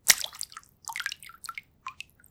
splash-small-quiet.wav